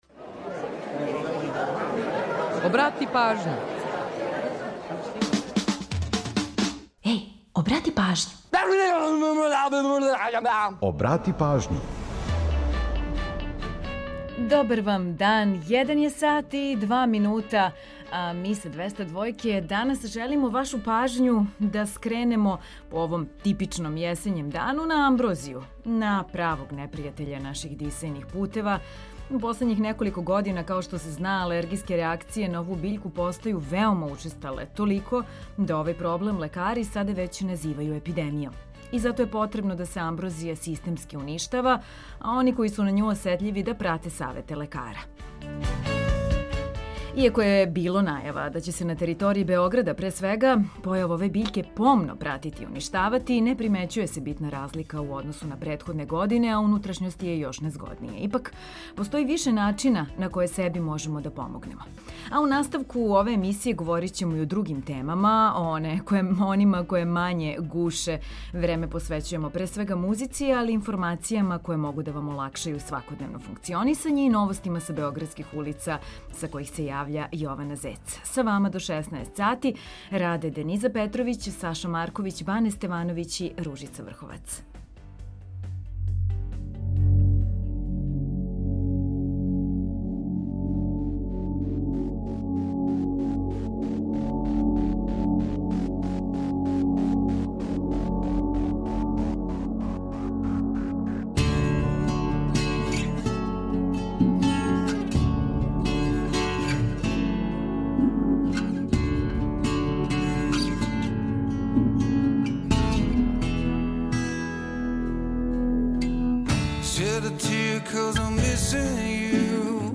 Време посвећујемо музици, информацијама које могу да вам олакшају свакодневно функционисање и новостима са београдских улица.